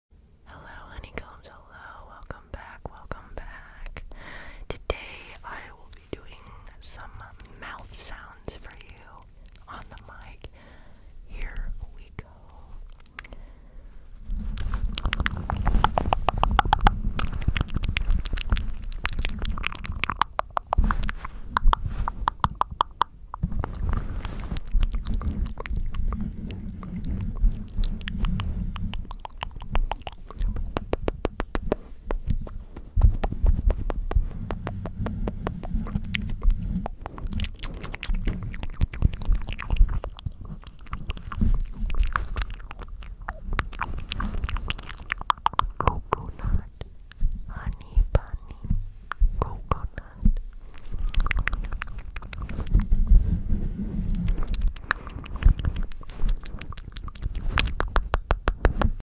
mouth sounds for a follower! sound effects free download